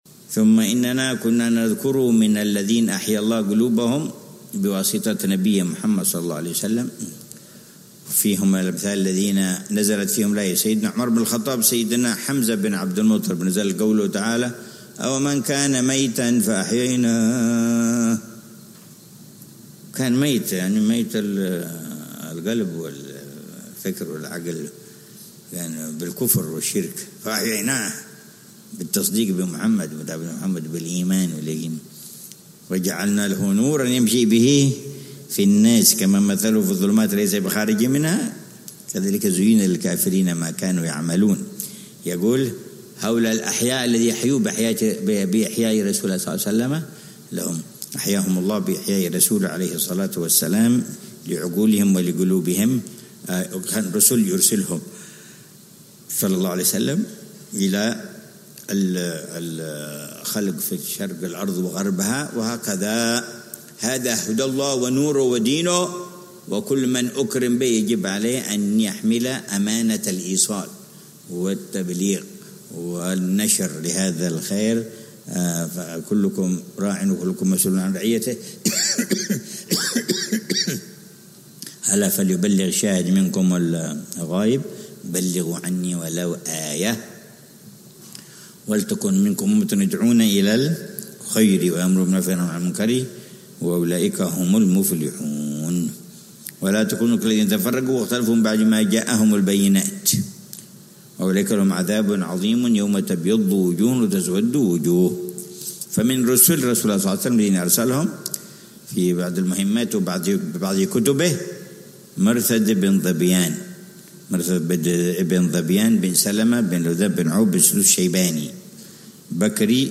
من دروس السيرة النبوية التي يلقيها العلامة الحبيب عمر بن محمد بن حفيظ، ضمن دروس الدورة التعليمية الحادية والثلاثين بدار المصطفى بتريم للدراسات